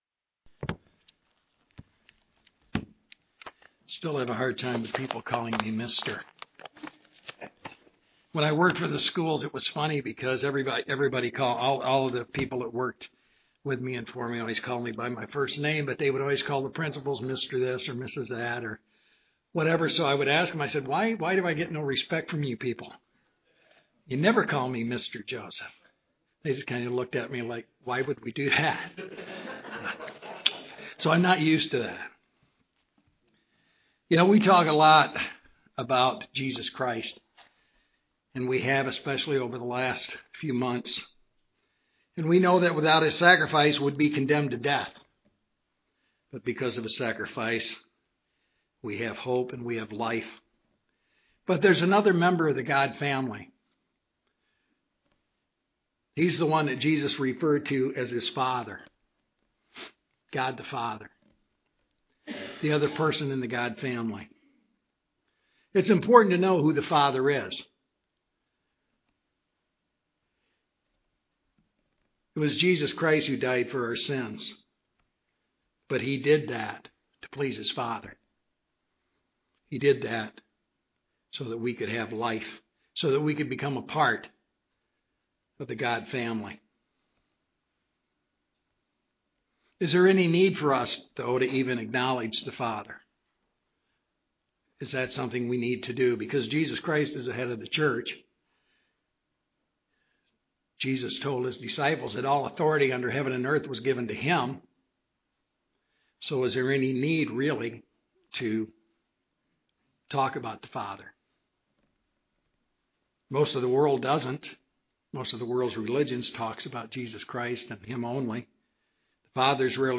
This sermon examines His role and place in the Godhead.